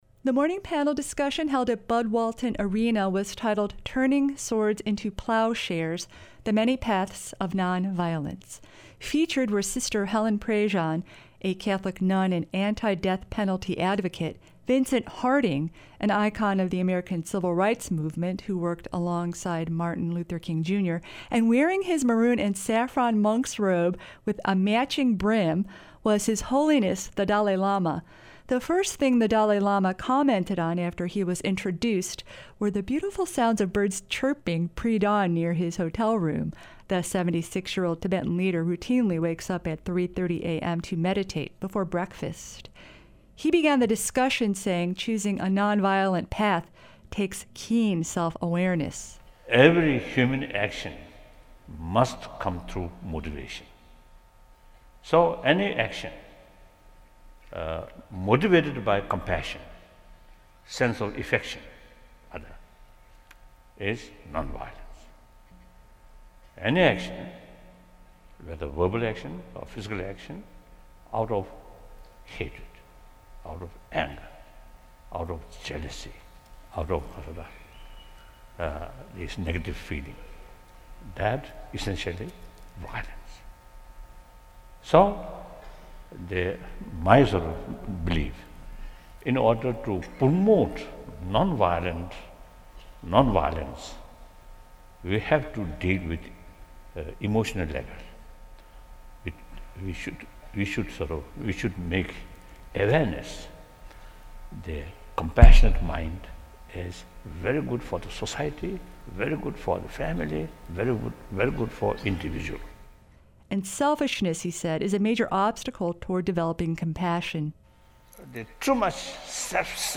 UA Dalai Lama Panel
Nonviolence_Panel.mp3